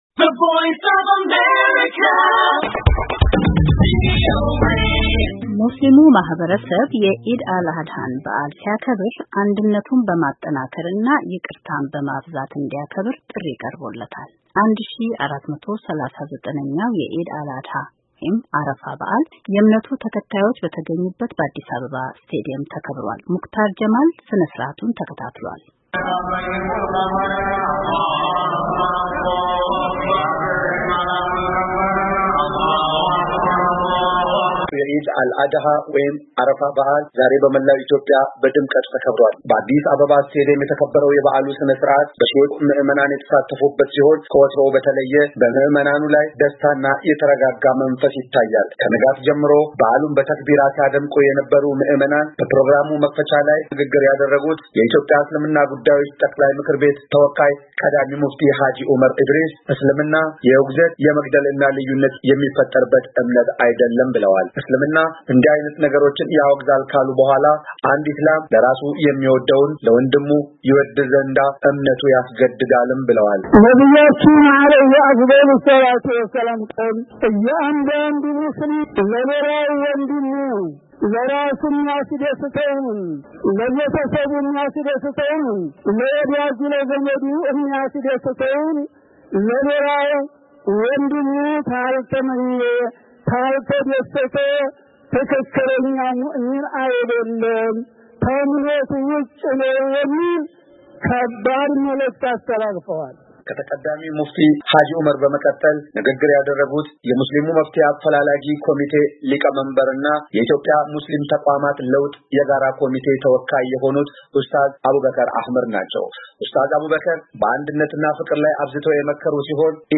1439ኛው የኢድ አል አድሃ /አረፋ/ በዓል የዕምነቱ ተከታዮች በተገኙበት በአዲስ አበባ ስታድዮም ተከበረ።